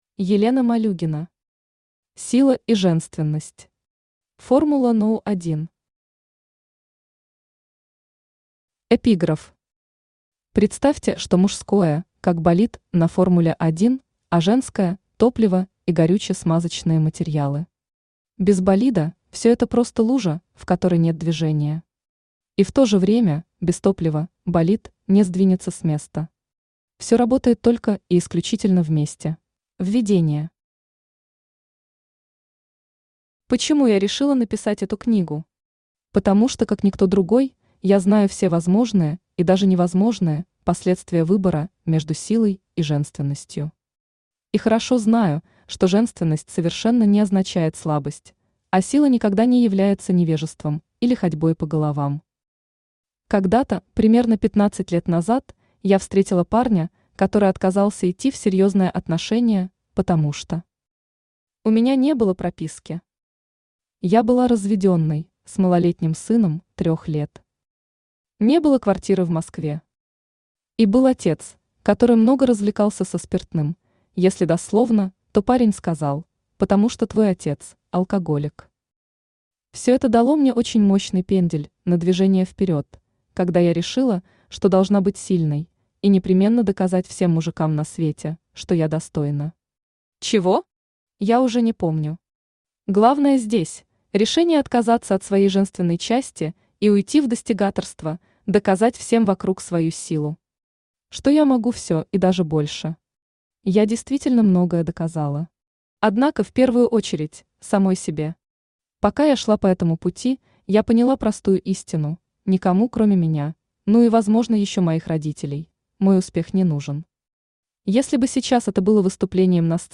Аудиокнига Сила и Женственность. Формула №1 | Библиотека аудиокниг
Формула №1 Автор Елена Викторовна Малюгина Читает аудиокнигу Авточтец ЛитРес.